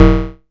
Vermona Perc 05.wav